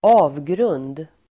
Uttal: [²'a:vgrun:d]